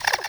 sci-fi_hacking_aliens_02.wav